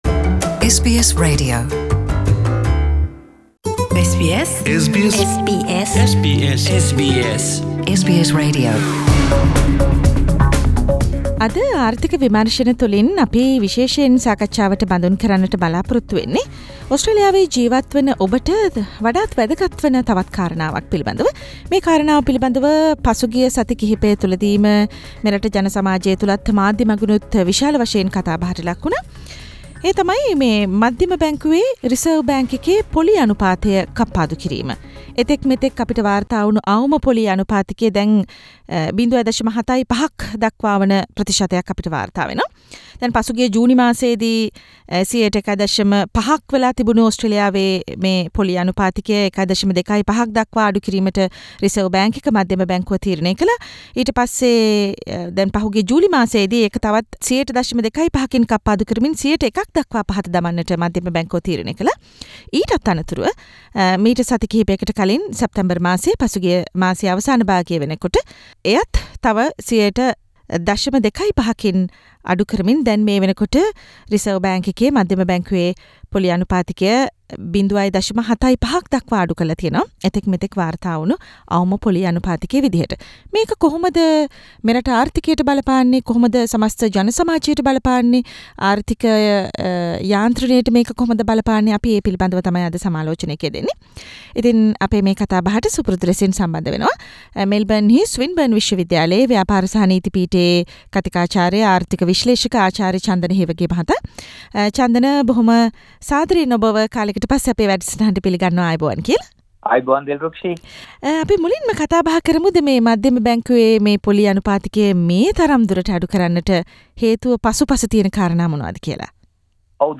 ඕස්ට්‍රේලියානු මධ්‍යම බැංකුව විසින් සිදුකල ණය පොලී ප්‍රතිශතයේ කප්පාදුව සාමාන්‍ය ජනතාවට සහ රටට බලපාන අයුරු මෙම සාකච්චාවෙන් විග්‍රහ කෙරේ.